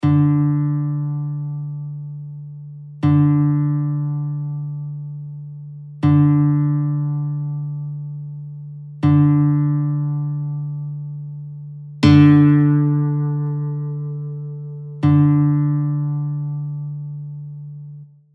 Шестиструнна гітара або кобза, стрій СОЛЬ
4-та струна – ре, D (mp3):
Hitara_6-str_G-strij_4_D3.mp3